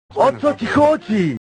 Worms speechbanks
leavemealone.wav